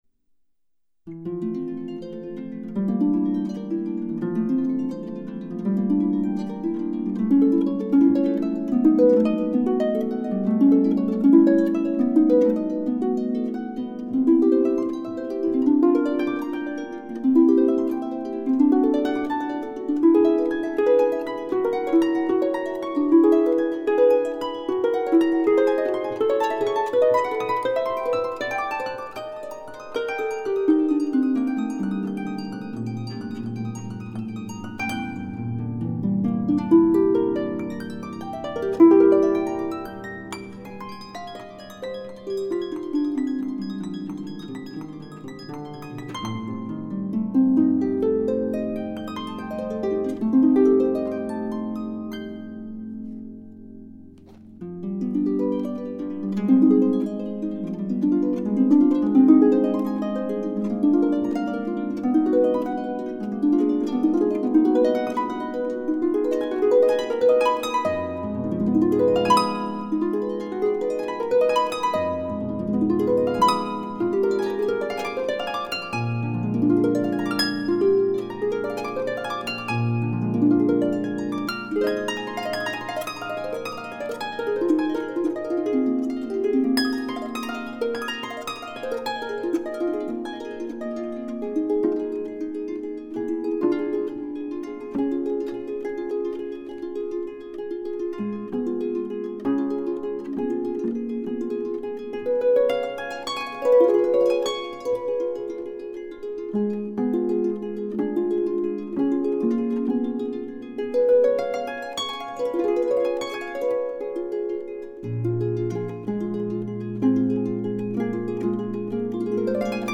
Harpist 2
harp2-11.mp3